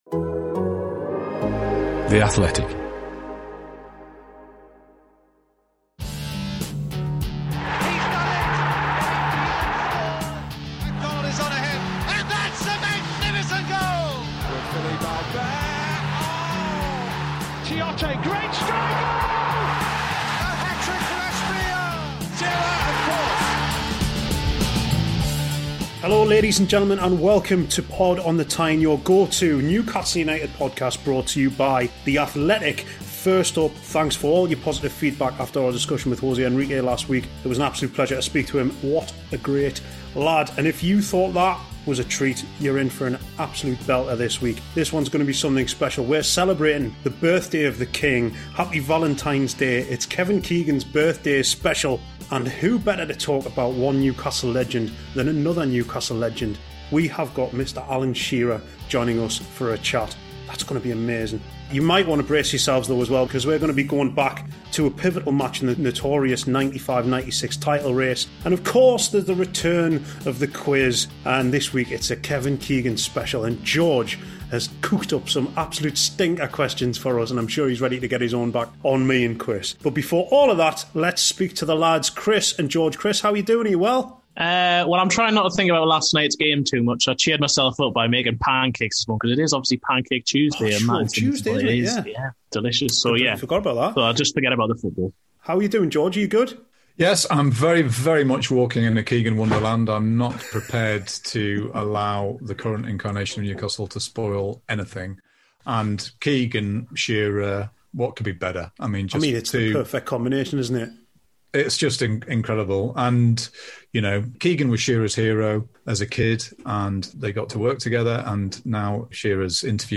Yes, the one and only Alan Shearer is our special guest and, hot off the back of his Athletic exclusive interview with "the Messiah", he relives the highs and lows of his idol's three spells at NUFC.